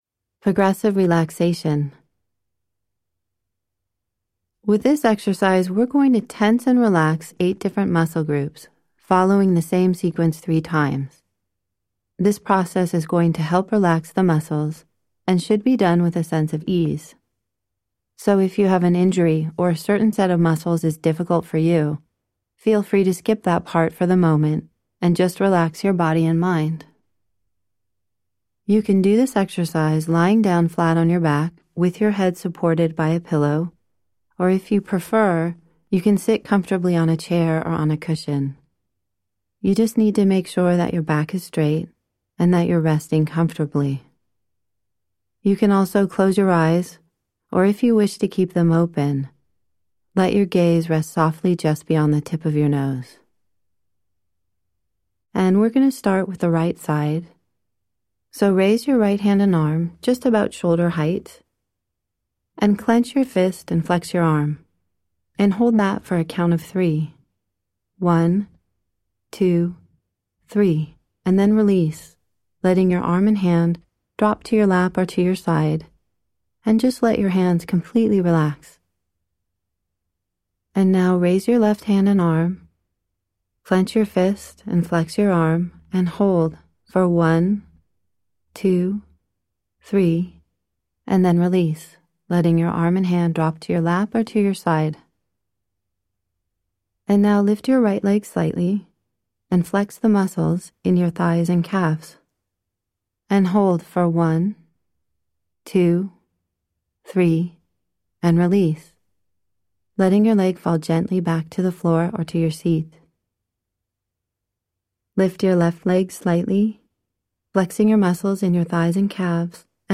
Guided Meditations & Mindfulness